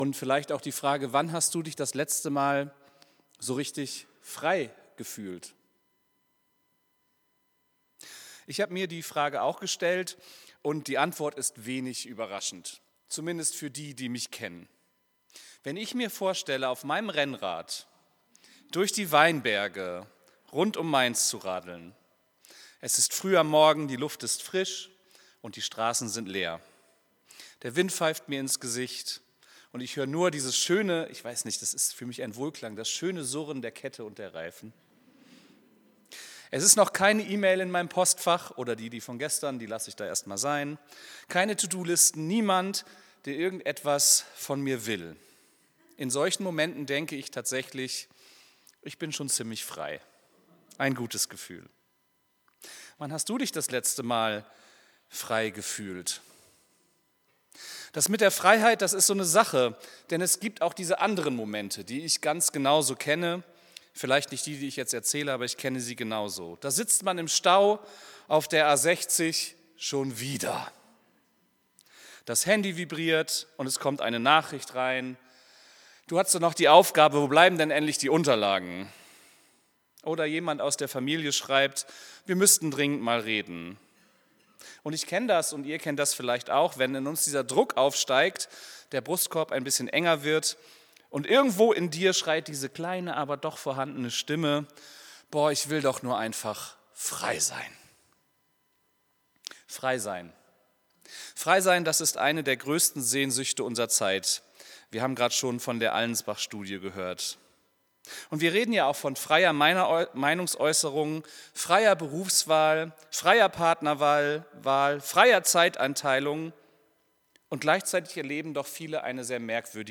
Predigt vom 15.03.2026